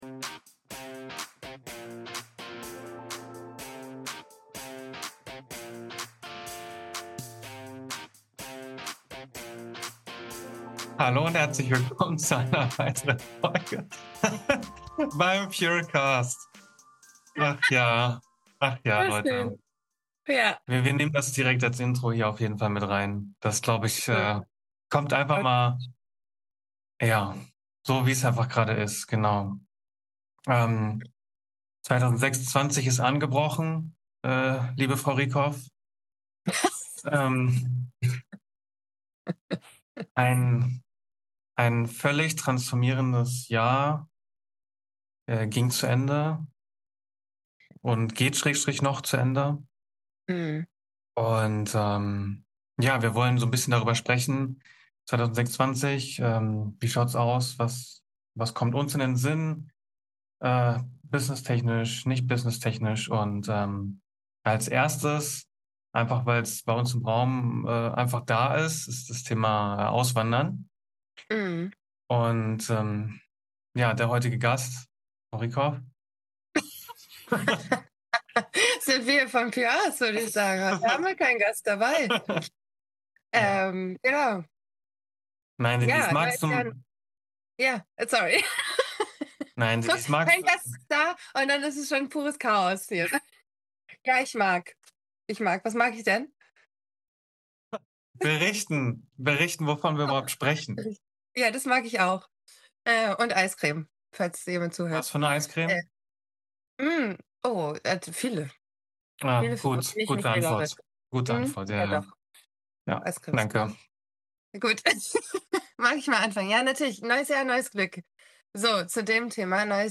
Eine Folge wie eine ehrliche Sprachnachricht: wild, warm und voll echter Gedanken.